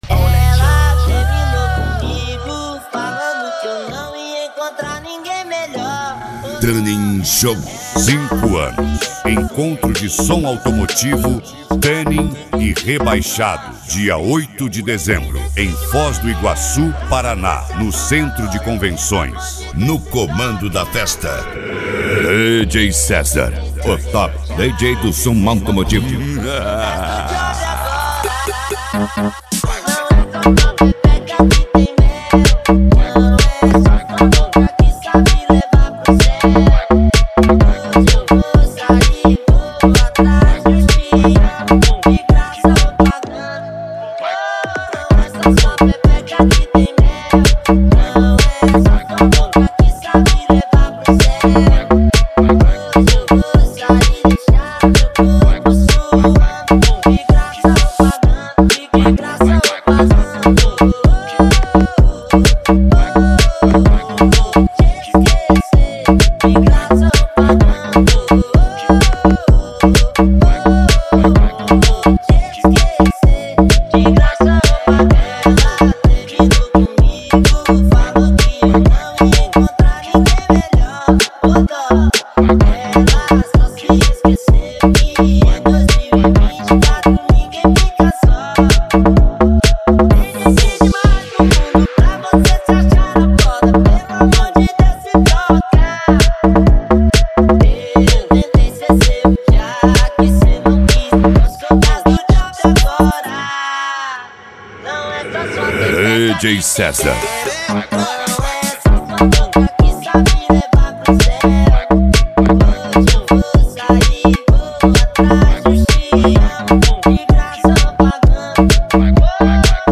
Mega Funk